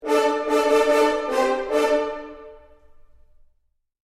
Sound Effects
Trompeta Medieval